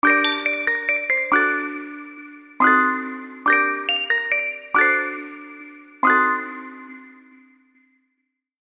130+ Serum presets created for Hip Hop, RnB, and Soul